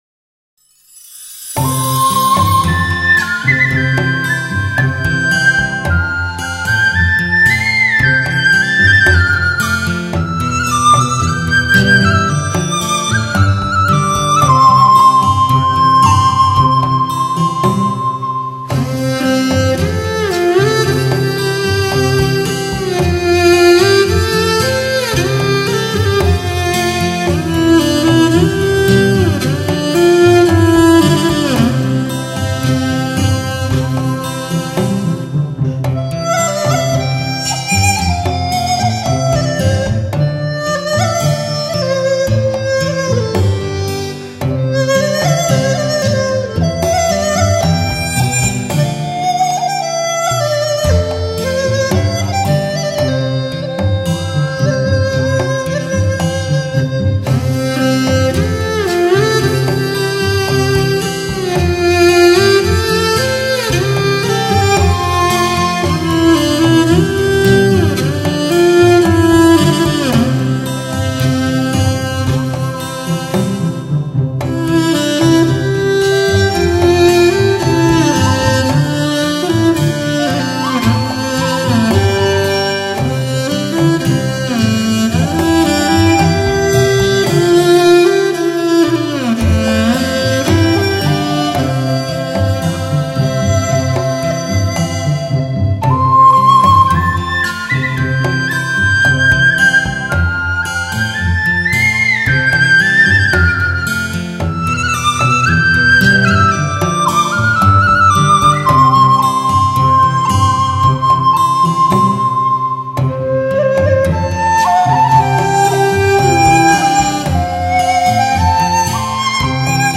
笛，是民间流传最广的吹管乐器，其音乐高亢、清脆，曲笛音色较淳厚、
圆润。马头琴的琴声幽怨而旷远，低回而婉转，如泣如诉，催人泪下
将一个个如诗如画的音乐意境描绘得栩栩如生；潮涌涧流，芦苇跌宕，
飞鸟翊云，牧童放歌，月夜游湖，都在曲中勾勒得细腻动人，如幻如真